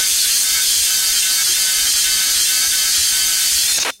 Buzzing Wire
A persistent electrical wire buzzing with intermittent crackle and 60Hz hum
buzzing-wire.mp3